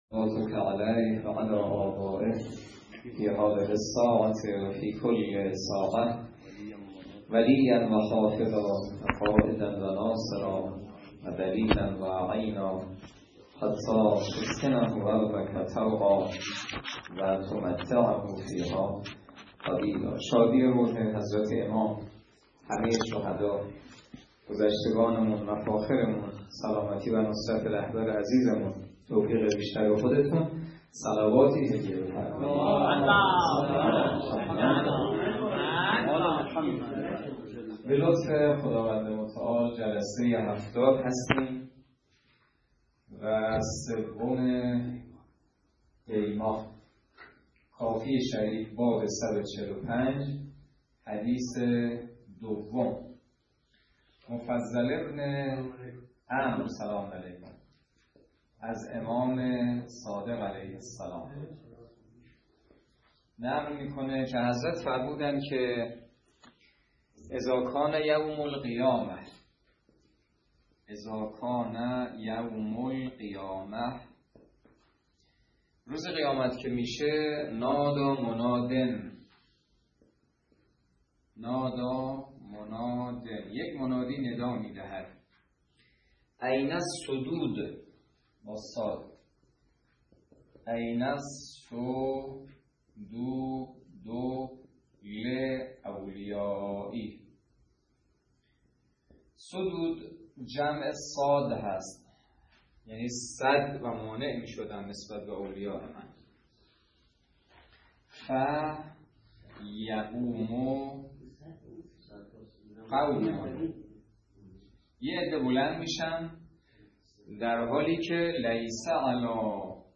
درس فقه الاجاره نماینده مقام معظم رهبری در منطقه و امام جمعه کاشان - سال سوم جلسه هفتادم